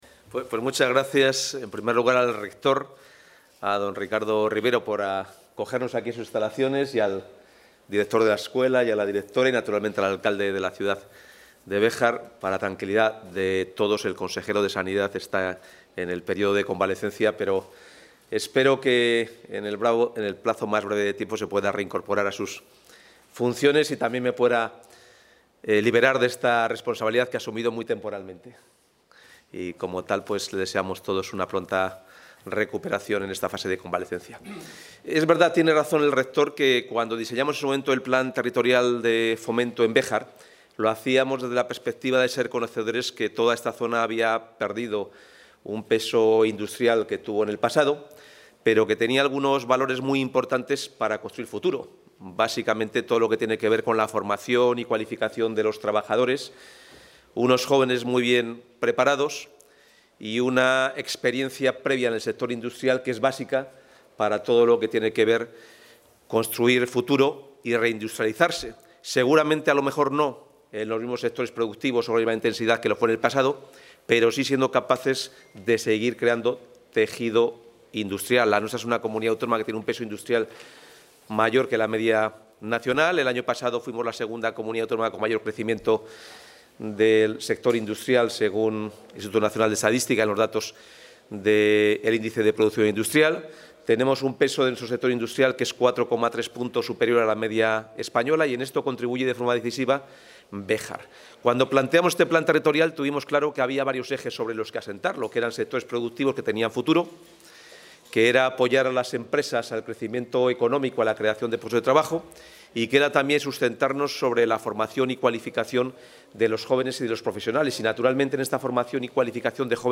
Intervención del consejero.
El consejero de Economía y Hacienda, Carlos Fernández Carriedo, y el rector de la Universidad de Salamanca, Ricardo Rivero, han participado en la inauguración de los nuevos laboratorios de la Escuela Técnica Superior de Ingeniería Industrial de la Universidad de Salamanca en Béjar.